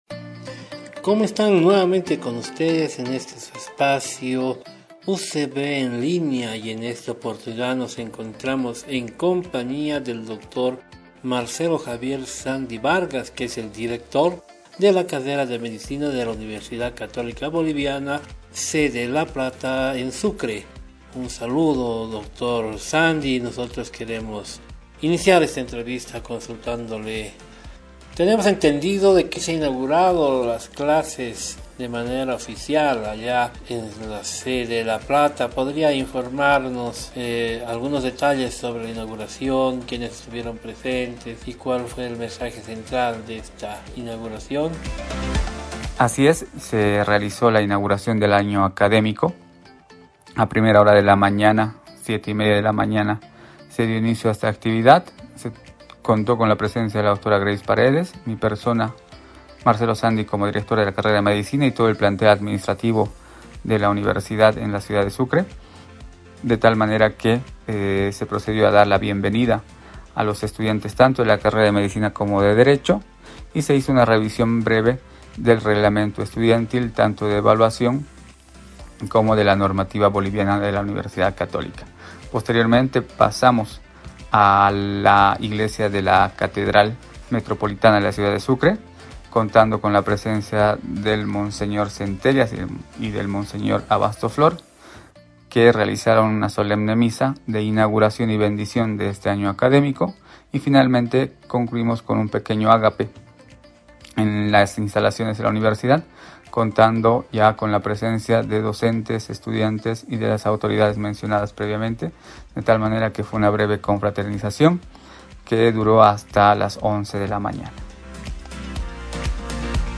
A continuación, les presentamos la entrevista